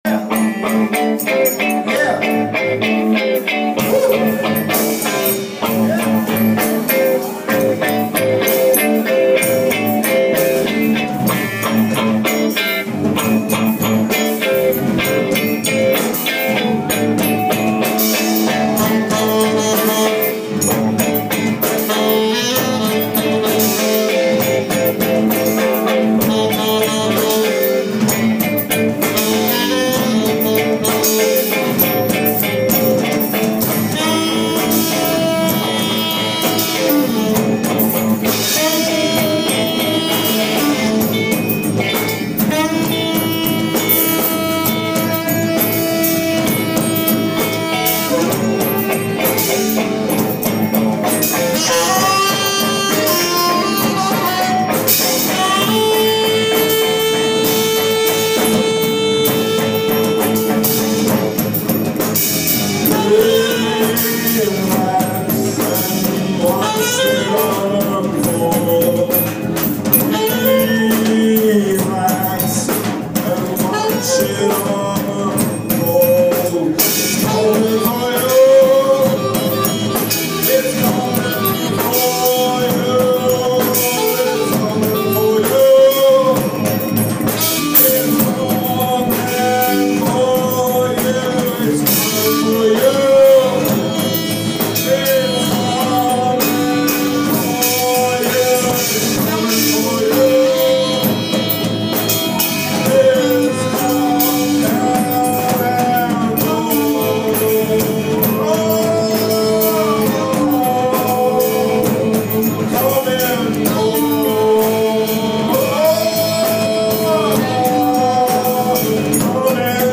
MOST MUSIC IS IMPROVISED ON SITE
Terricloth Underground typmanis/sitar
sax/flute